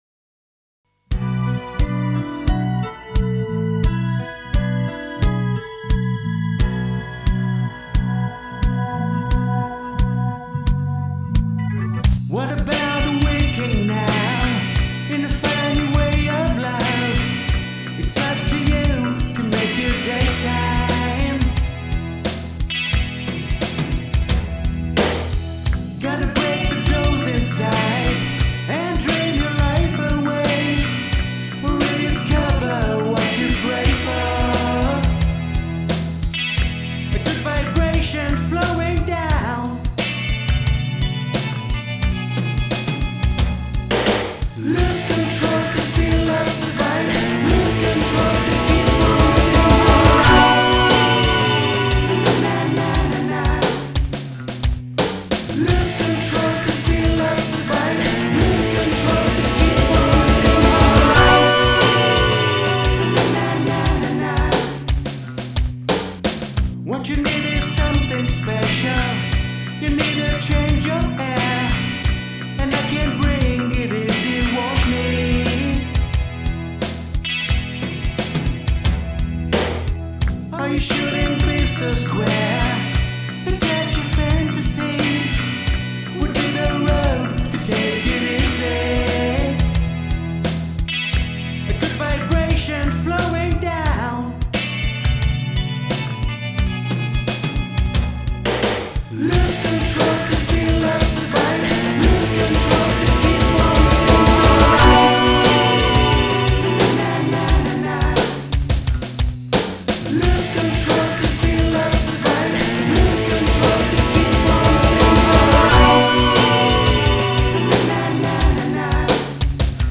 dans le plus pur style Brit Pop des années 1960 à 1980
Synth & Brit Pop